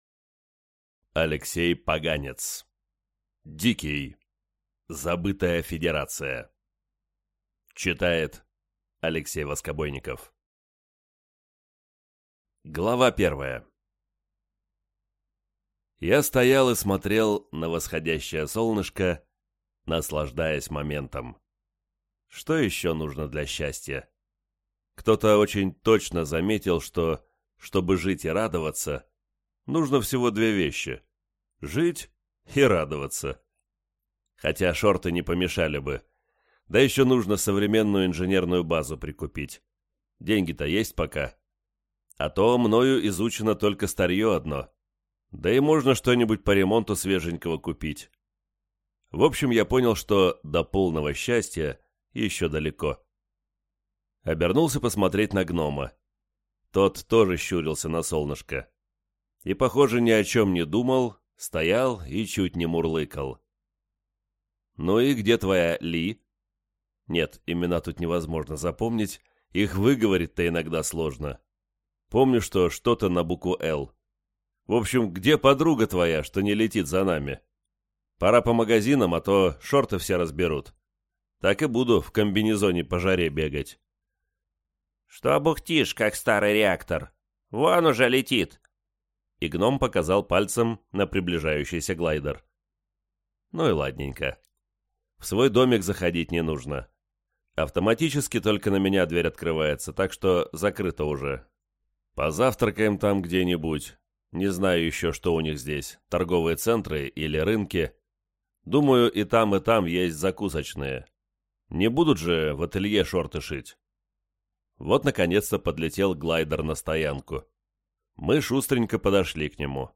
Аудиокнига Дикий. Забытая федерация | Библиотека аудиокниг
Прослушать и бесплатно скачать фрагмент аудиокниги